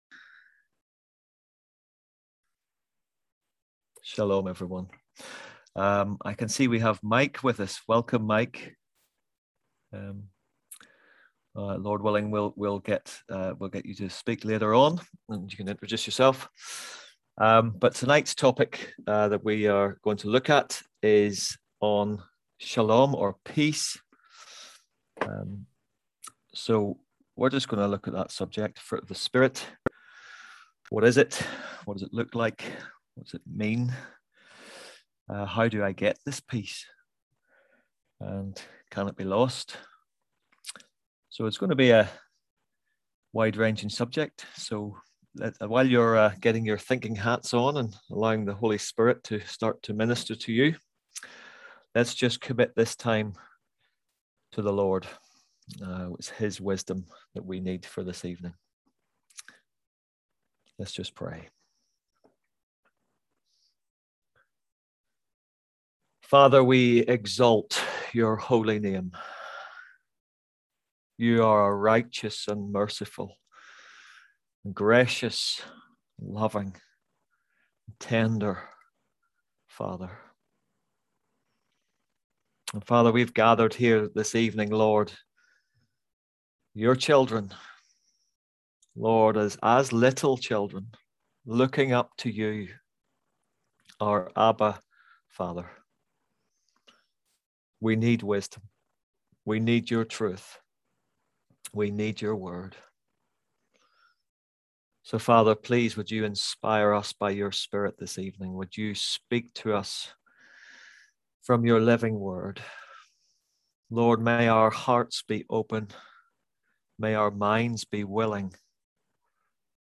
On October 18th at 7pm – 8:30pm on ZOOM